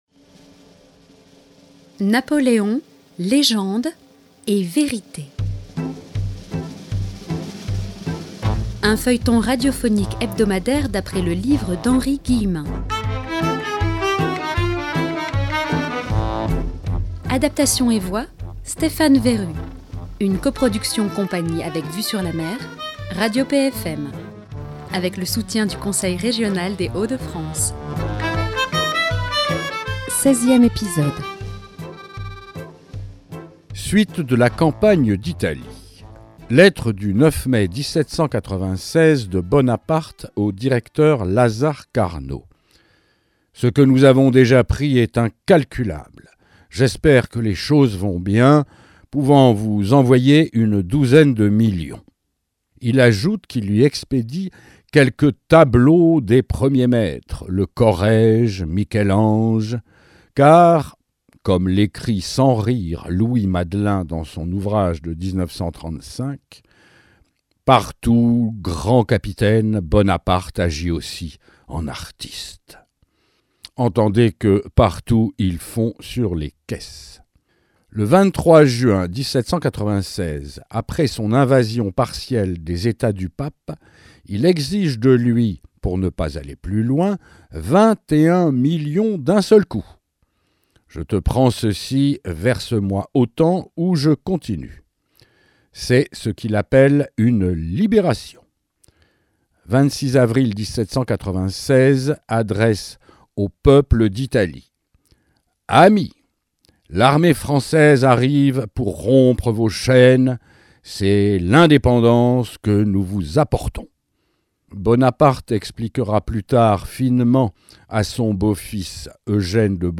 a adapté en feuilleton radiophonique l’ouvrage de l’historien iconoclaste Henri Guillemin